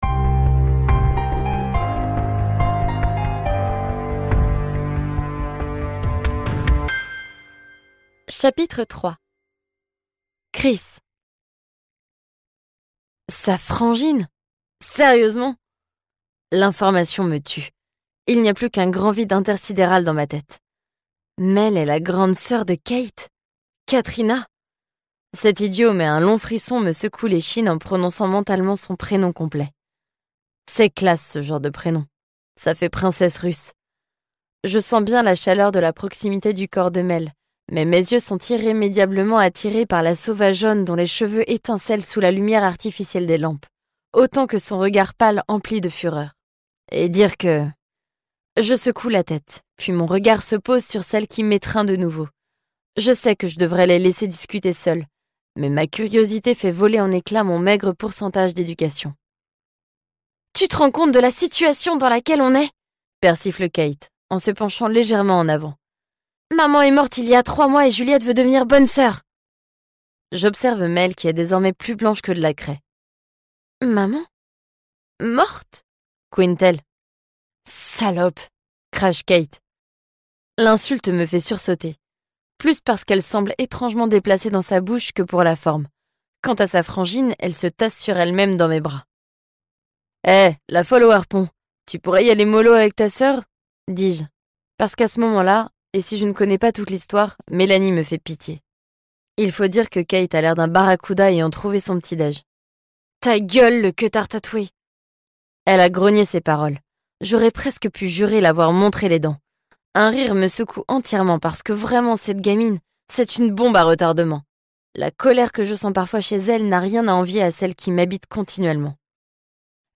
» - Smells like rock Ce livre audio est interprété par une voix humaine, dans le respect des engagements d'Hardigan.